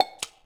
lg_se_camera_click_3.wav